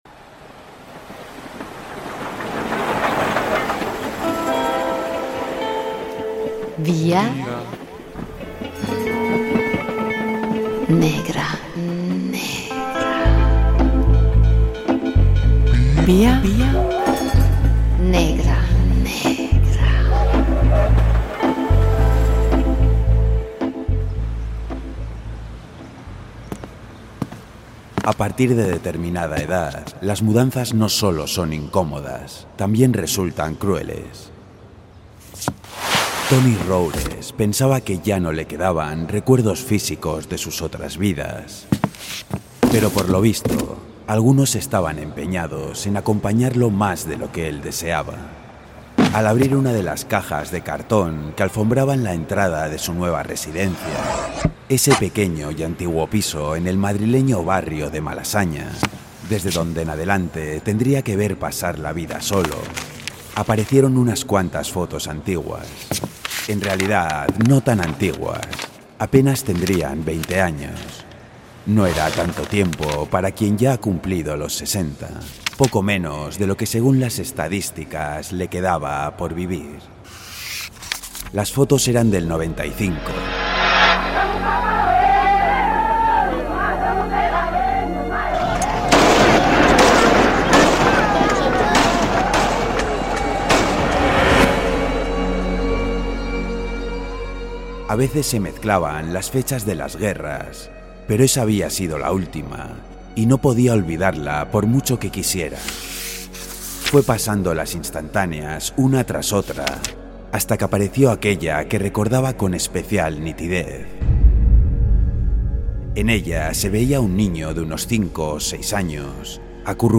Radio Euskadi BOULEVARD "Ruleta macabra" Última actualización: 02/06/2017 13:27 (UTC+2) Sonorizamos un fragmento de la última novela de la escritora Marta Robles, su primera incursión en el mundo de la novela negra.